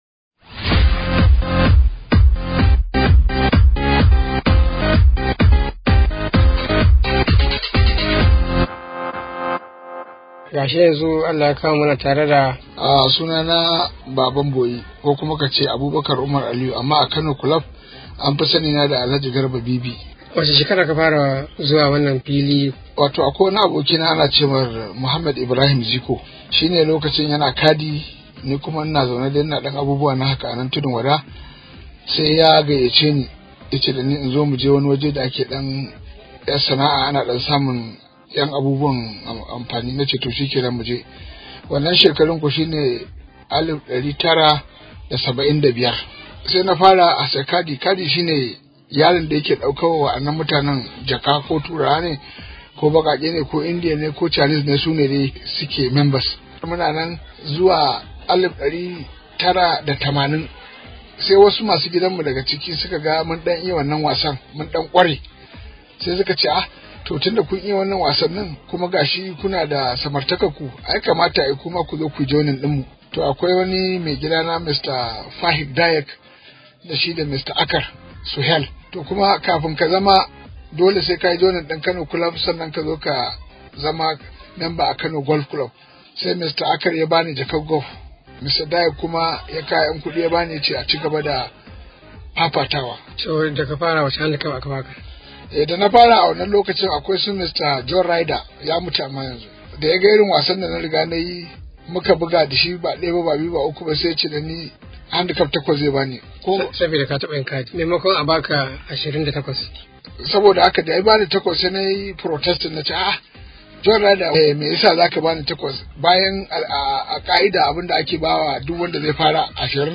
Daga filin wasan kwallon Golf na Kano Club